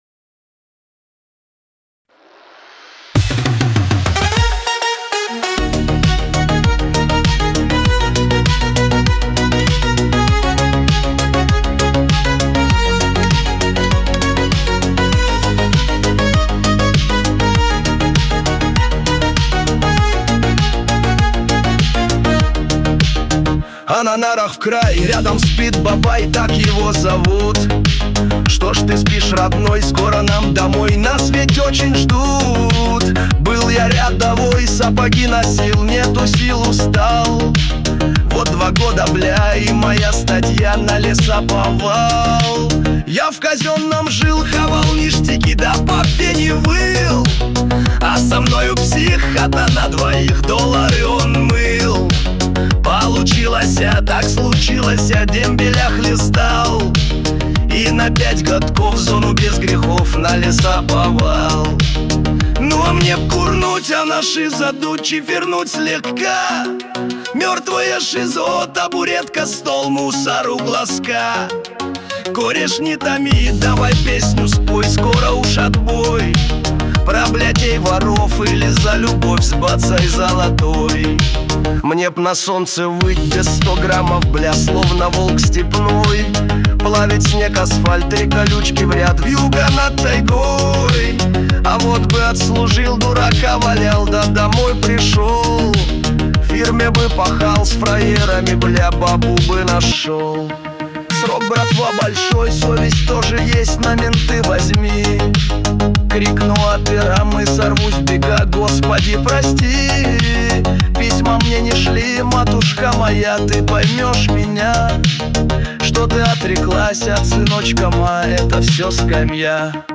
Качество: 192 kbps, stereo